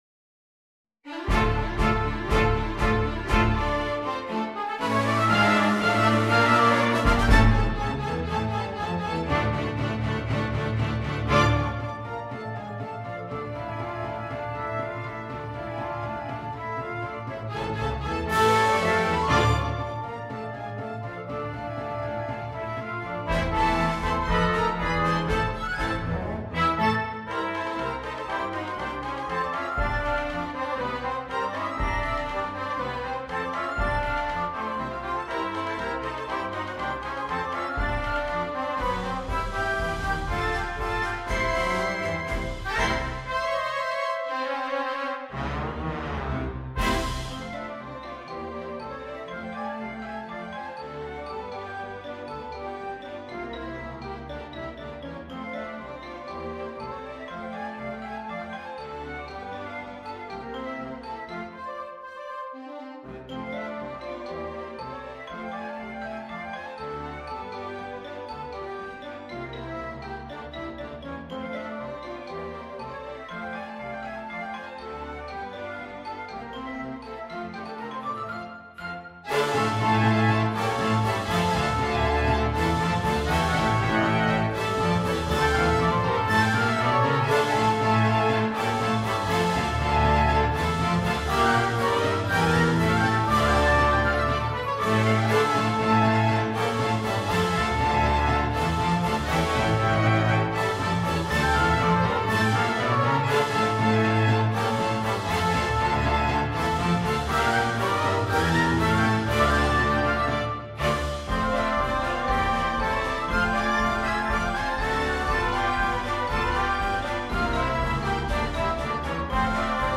Style March
Instrumentation Salon Orchestra